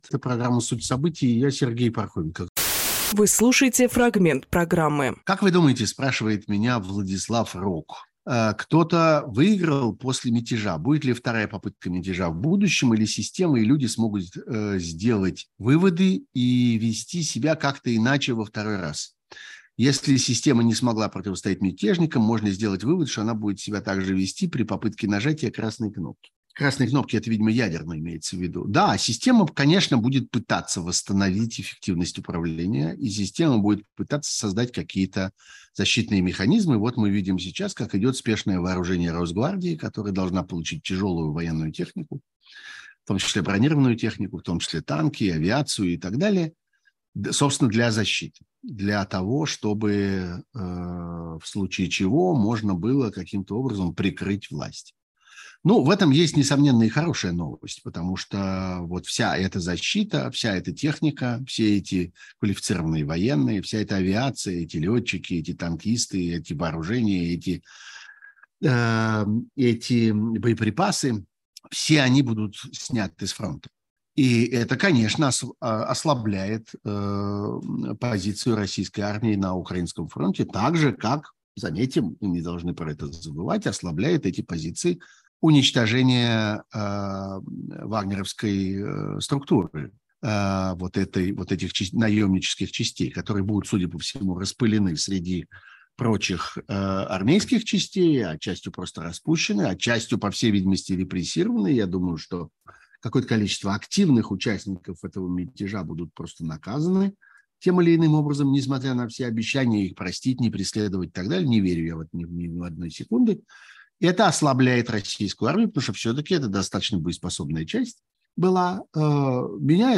Сергей Пархоменкожурналист, политический обозреватель
Фрагмент эфира от 30.06.23